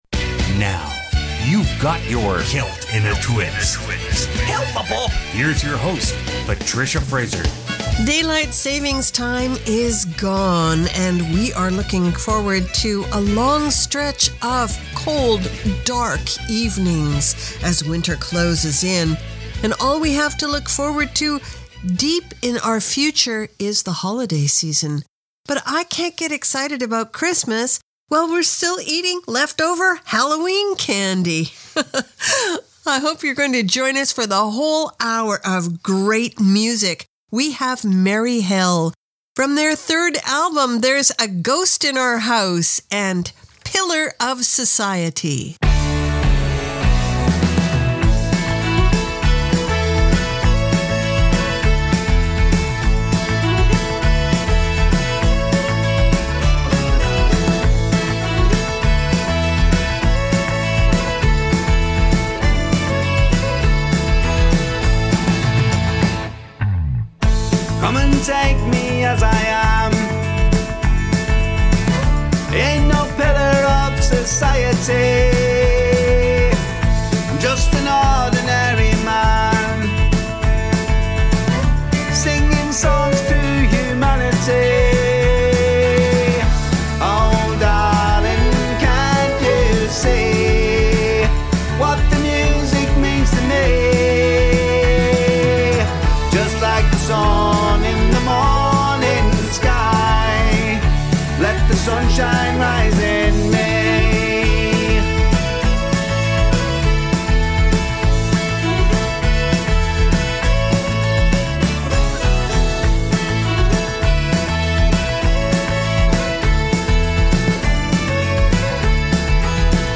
Canada's Contemporary Celtic Radio Hour
Latin Celtic
Czech Celtic
Australian Celtic